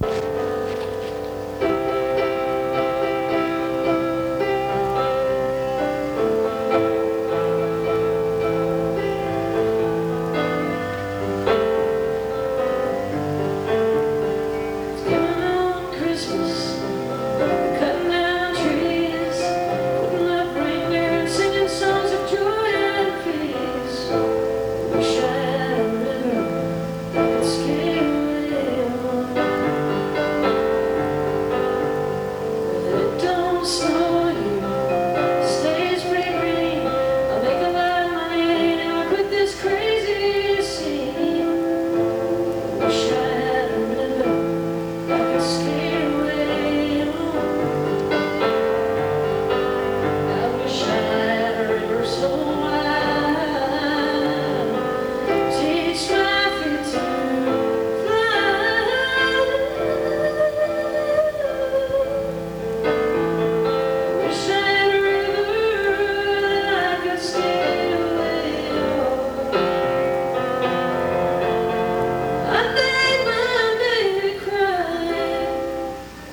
(band show)
(soundcheck)